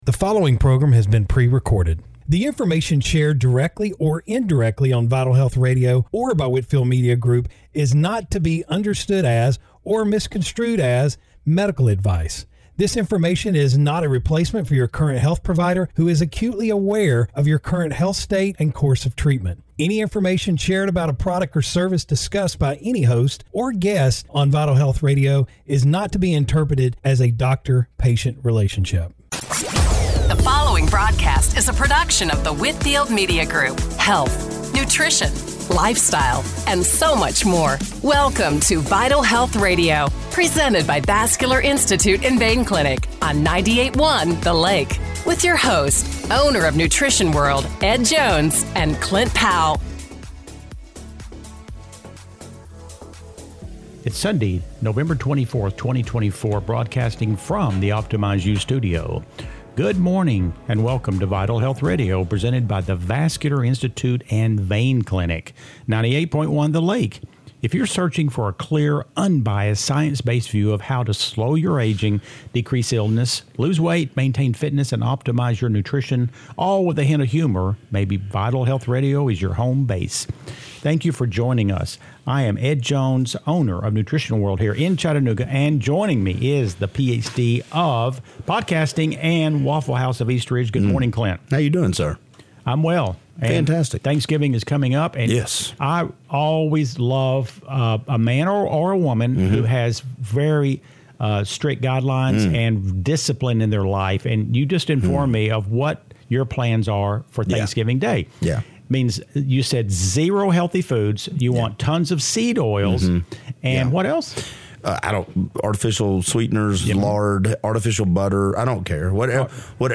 Radio Show – November 24, 2024 - Vital Health Radio